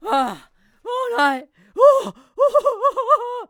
traf_scared.wav